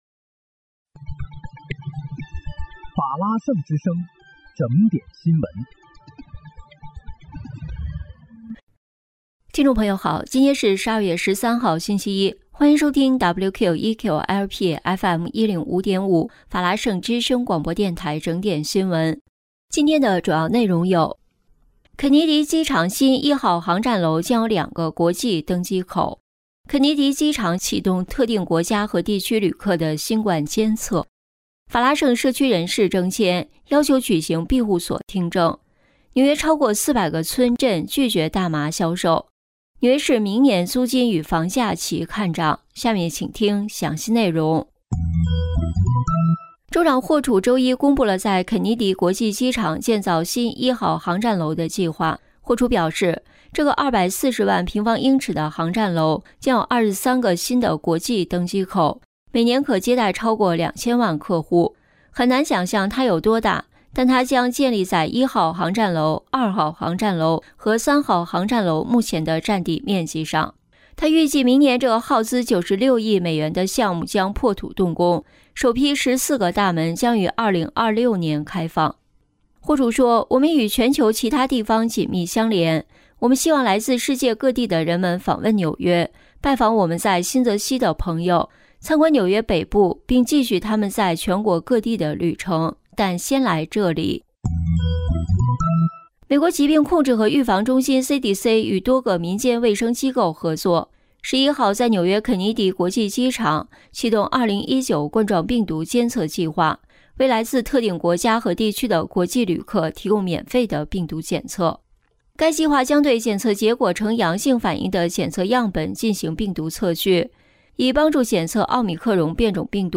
12月13日（星期一）纽约整点新闻
听众朋友您好！今天是12月13号，星期一，欢迎收听WQEQ-LP FM105.5法拉盛之声广播电台整点新闻。